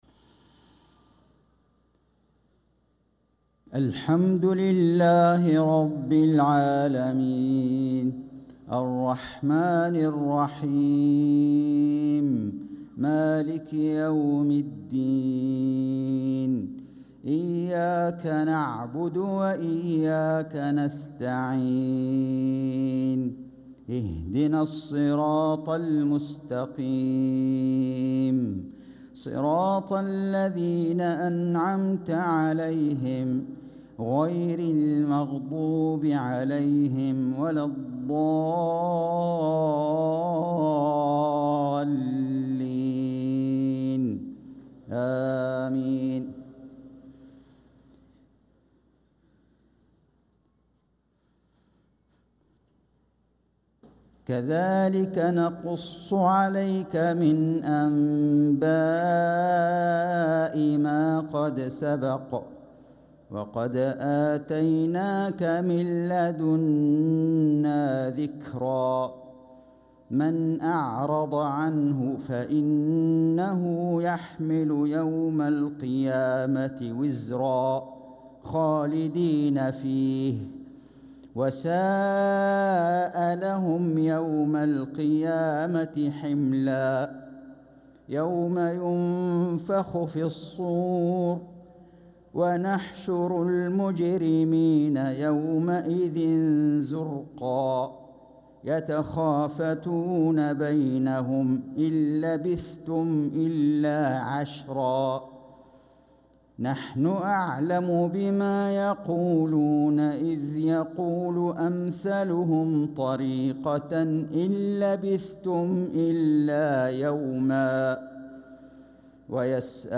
صلاة العشاء للقارئ فيصل غزاوي 2 جمادي الأول 1446 هـ
تِلَاوَات الْحَرَمَيْن .